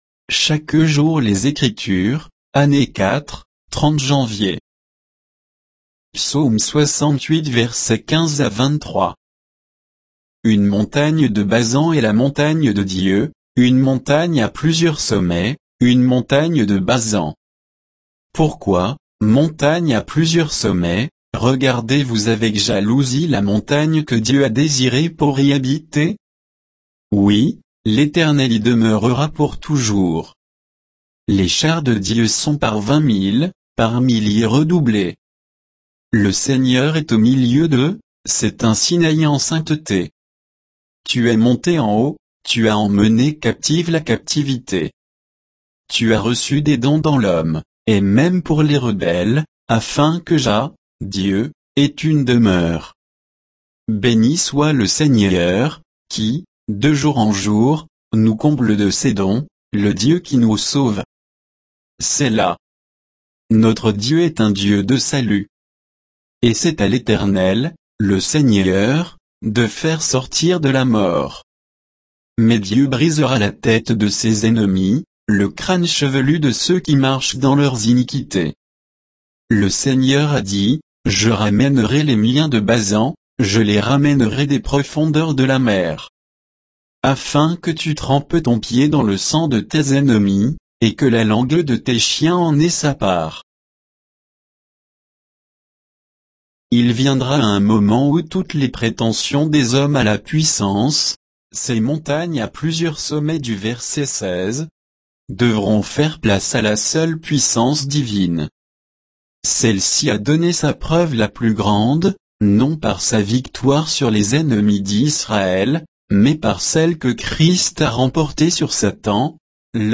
Méditation quoditienne de Chaque jour les Écritures sur Psaume 68